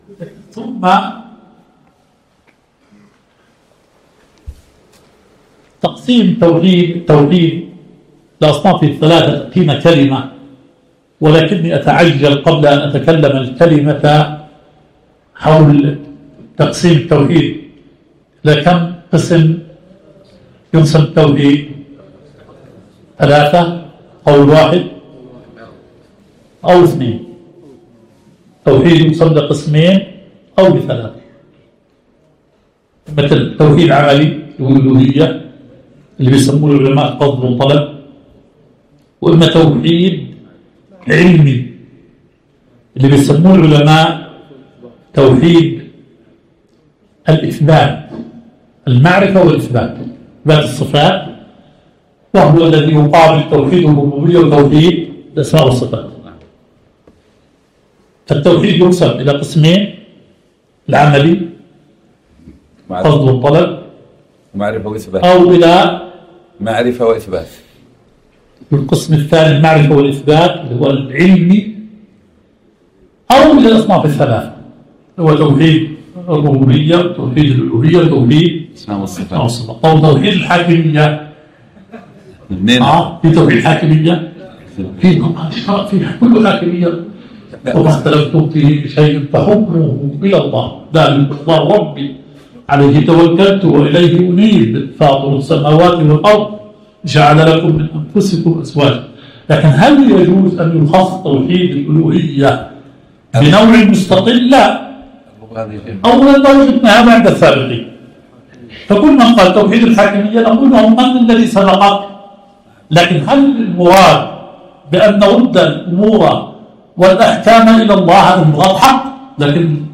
الدرس الثاني – شرح كتاب تجريد التوحيد المفيد للمقريزي